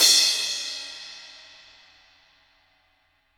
MIX CRASH5.wav